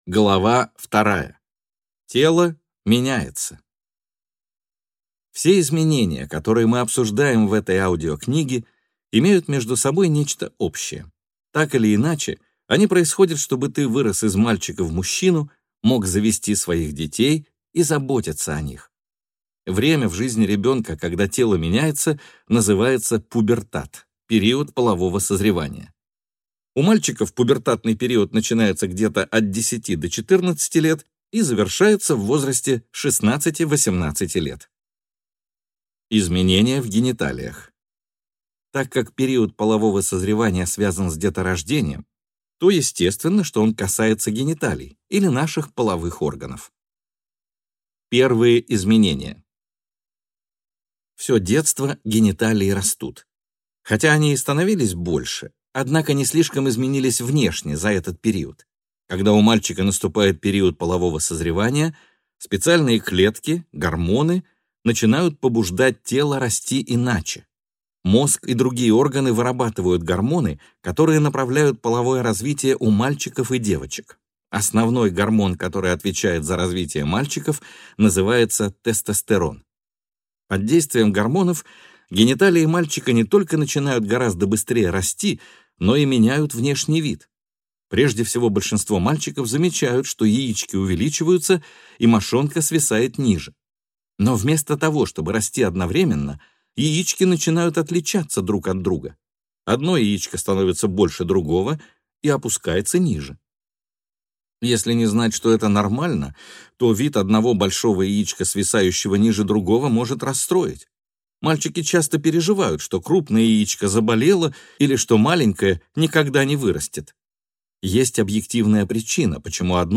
Аудиокнига Парням о важном. Все, что ты хотел знать о взрослении, изменениях тела, отношениях и многом другом | Библиотека аудиокниг